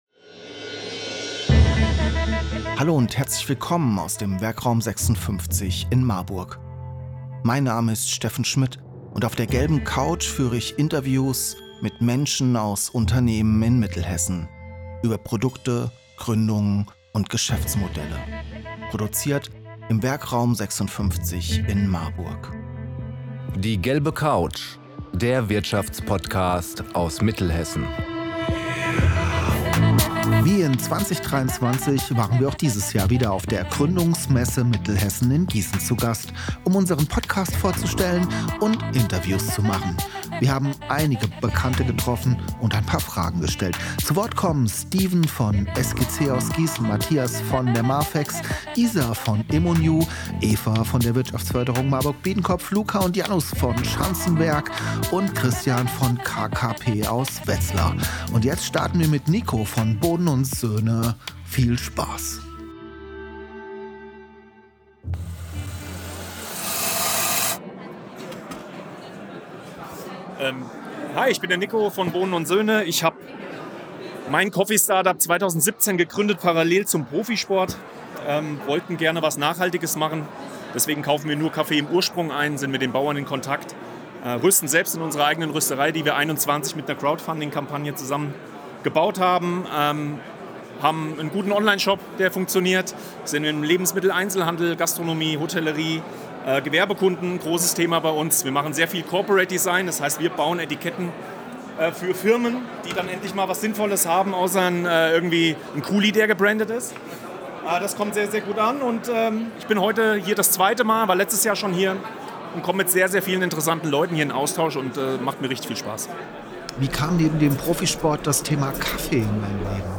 Wir waren wieder auf der Gründungmesse Mittelhessen 2024 in Gießen zu Gast und gingen direkt mal auf Stimmenfang. Erhaltet einen Einblick der verschiedenen Aussteller und Ausstellerinnen.